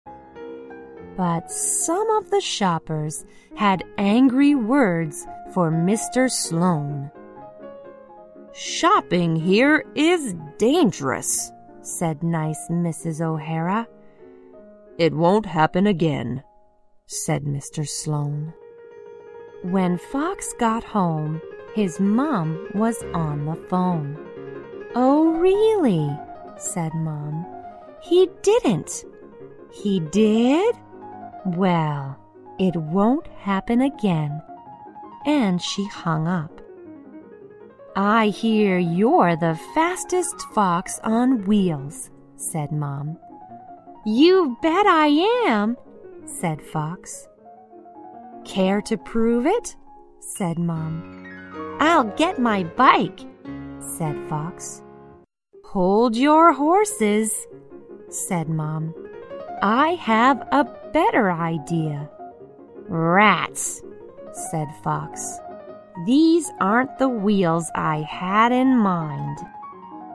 在线英语听力室小狐外传 第50期:投诉的听力文件下载,《小狐外传》是双语有声读物下面的子栏目，非常适合英语学习爱好者进行细心品读。故事内容讲述了一个小男生在学校、家庭里的各种角色转换以及生活中的趣事。